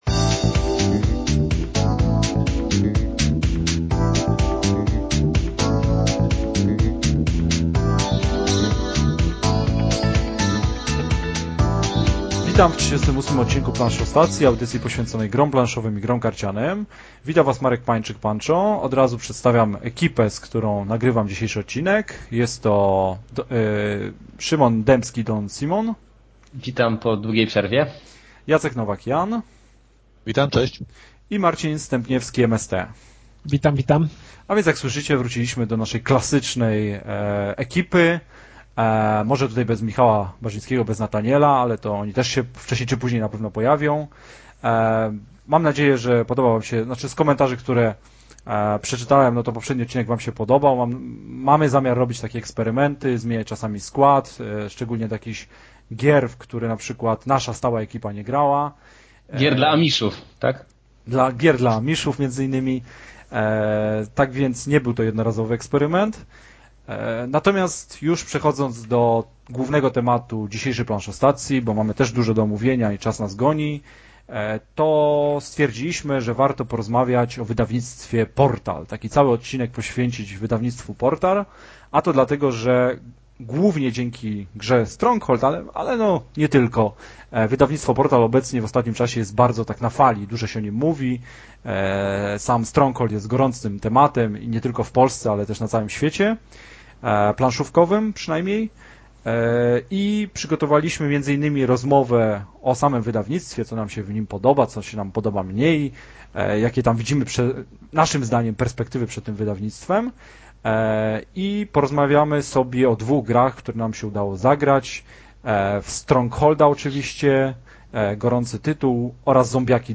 Wywiady 15 września 2009 17 komentarzy